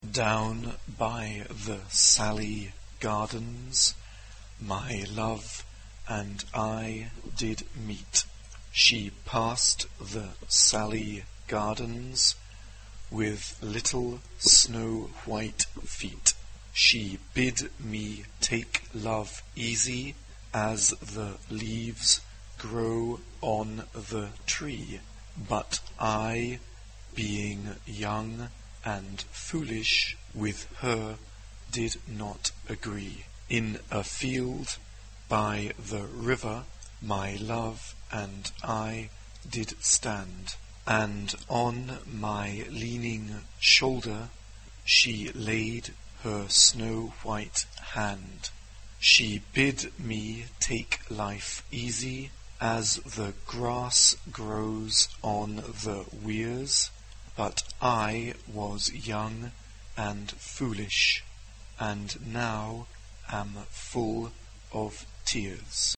SSA (3 voix égales de femmes) ; Partition complète.
Folklore. Chanson.
Caractère de la pièce : andante
Tonalité : ré majeur